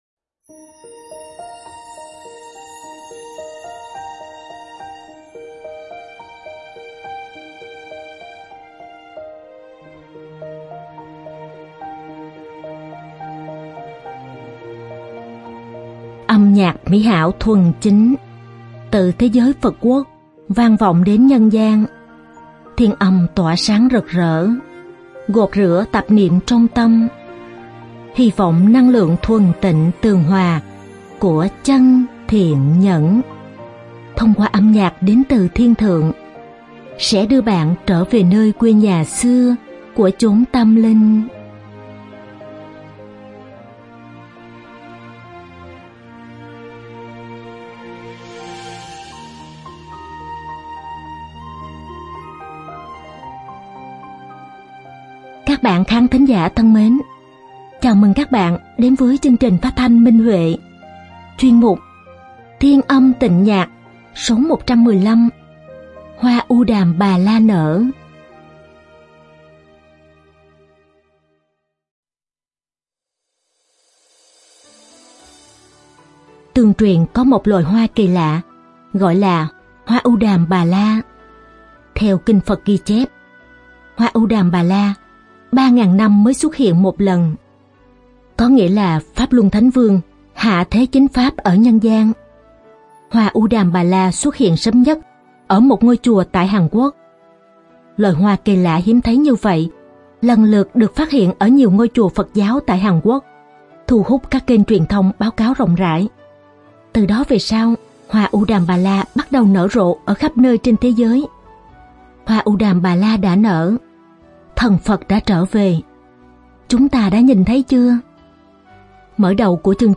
Âm nhạc mỹ hảo thuần chính